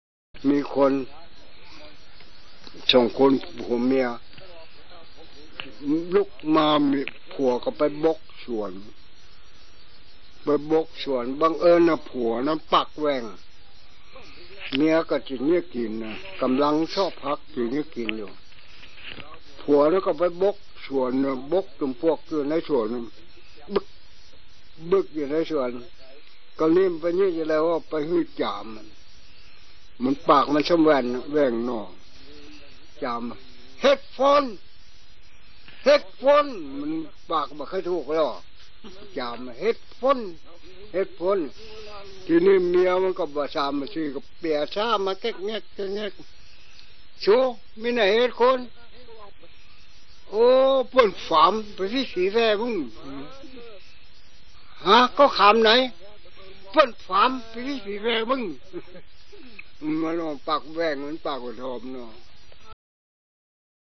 ขอบเขตและสื่อ : เทปรีล